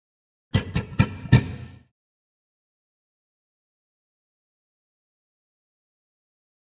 Gorilla Grunts. Four Quick, Low Growl-like Grunts. Close Perspective.